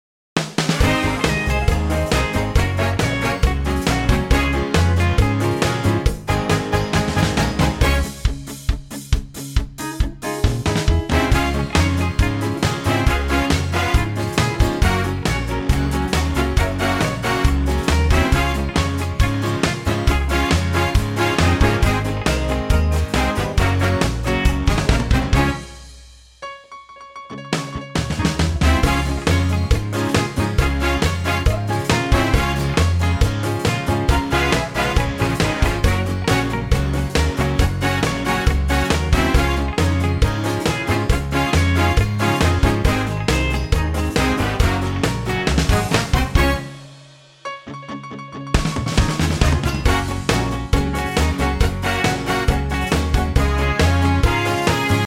Unique Backing Tracks
key Db 2:12
key - Db - vocal range - Ab to Ab